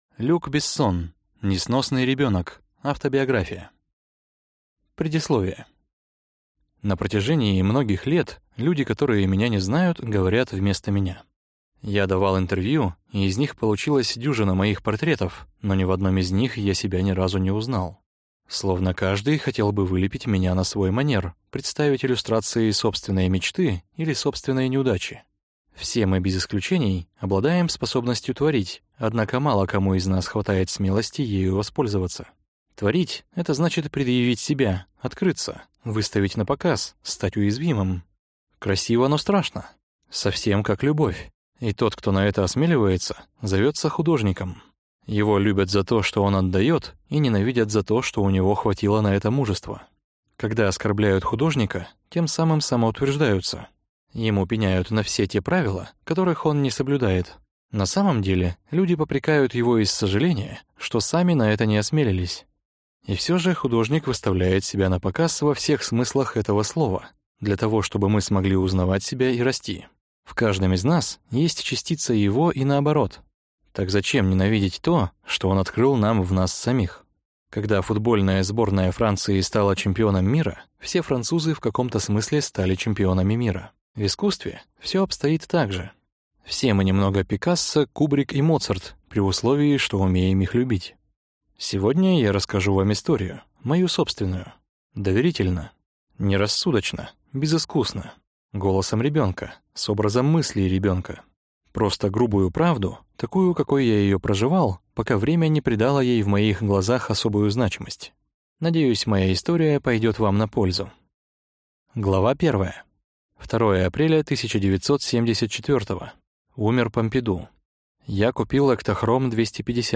Аудиокнига Несносный ребенок. Автобиография | Библиотека аудиокниг